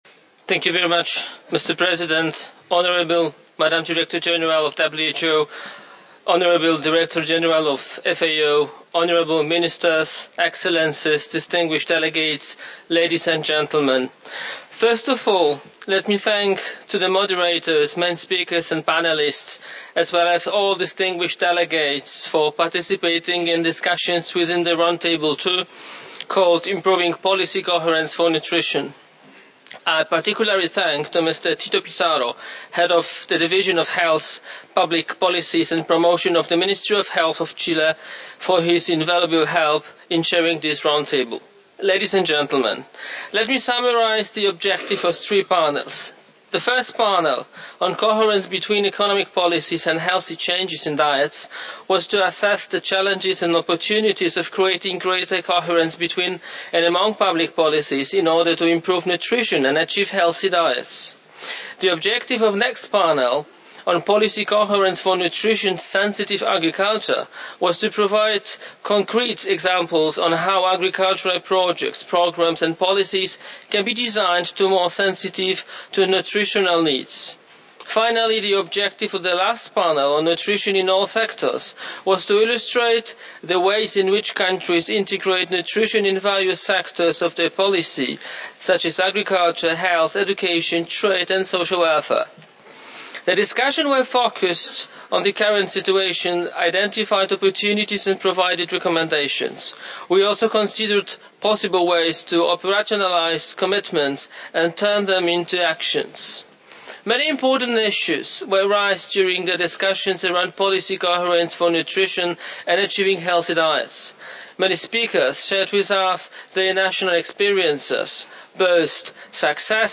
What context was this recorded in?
Second International Conference on Nutrition (ICN2), 19-21 November 2014